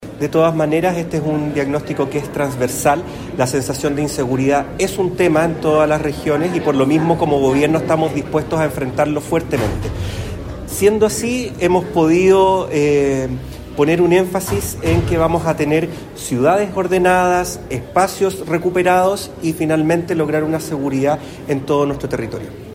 Delegado-Presidencial-Ruben-Quezada-2.mp3